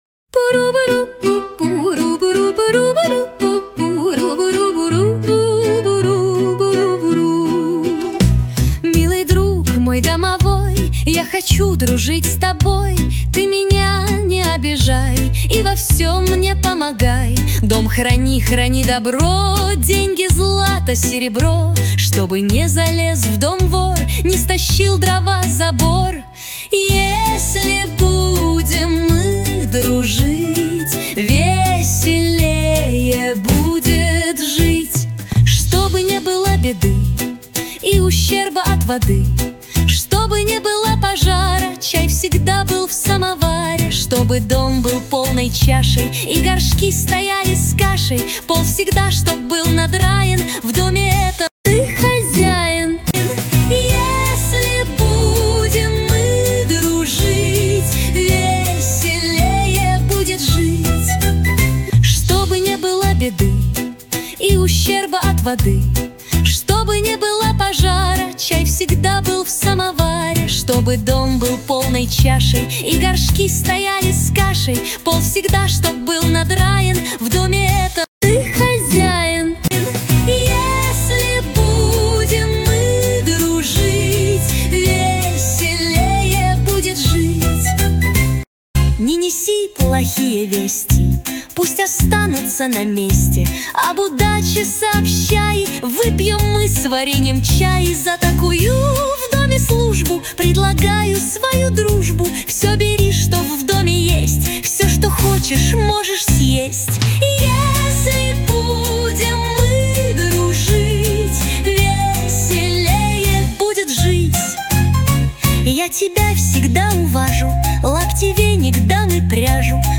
• Аранжировка: Ai
• Жанр: Фолк